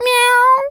pgs/Assets/Audio/Animal_Impersonations/cat_meow_01.wav at 7452e70b8c5ad2f7daae623e1a952eb18c9caab4
cat_meow_01.wav